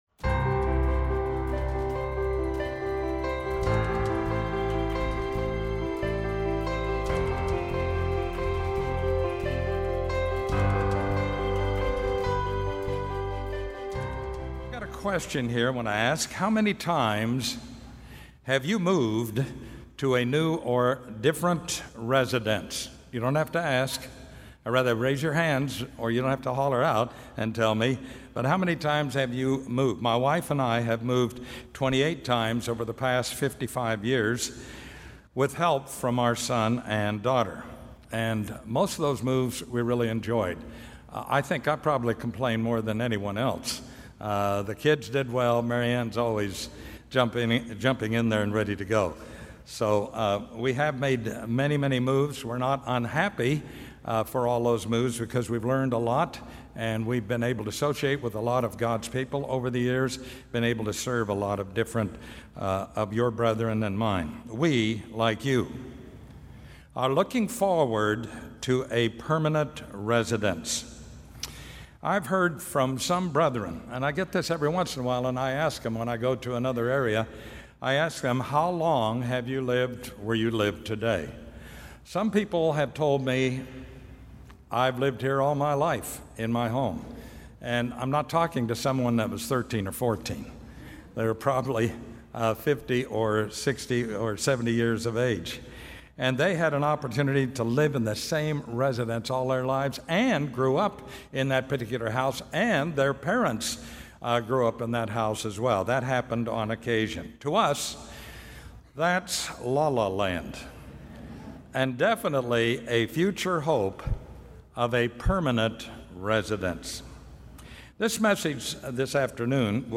This sermon was given at the Panama City Beach, Florida 2019 Feast site.